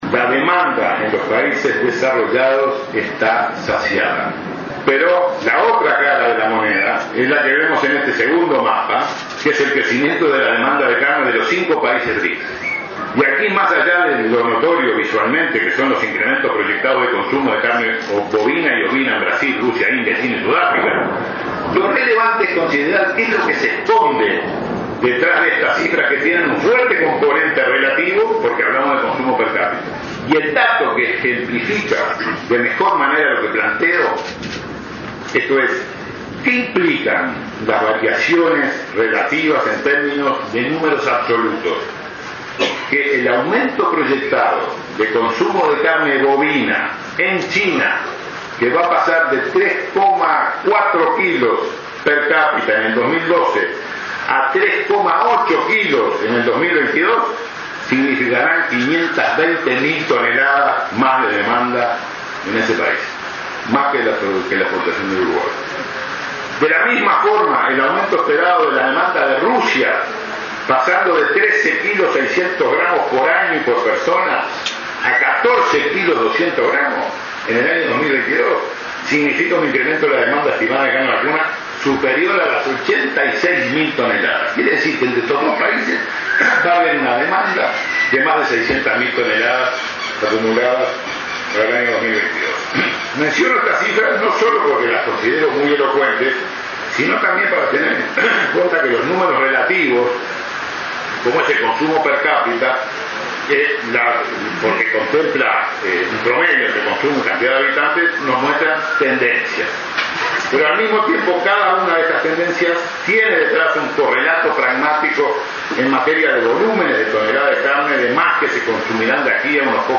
El mercado de carne de los países industrializados aparece “saciado”, pero se aprecia un incremento en el consumo por persona en China y Rusia, por lo cual se estima que, sumados, demandarán 600.000 toneladas más para 2022, indicó el canciller Rodolfo Nin Novoa, en su presentación en la Expo Prado 2017. Destacó que este es un sector de exportaciones uruguayas en continuo ascenso desde hace 15 años.